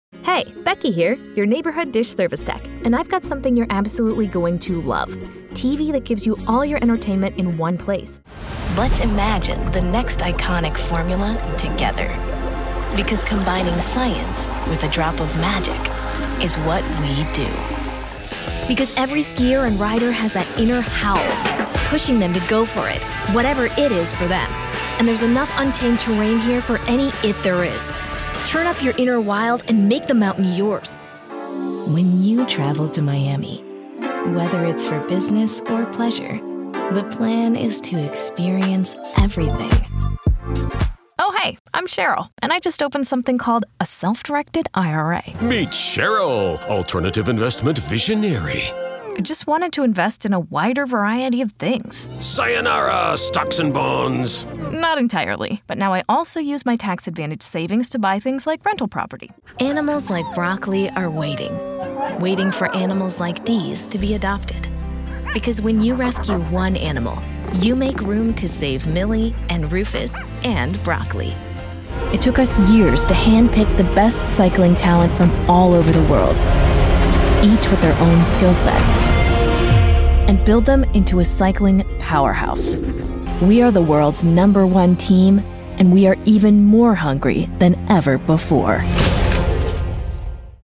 Female Voice Over, Dan Wachs Talent Agency.
Current, Modern, Young Mom, Heartfelt.
Commercial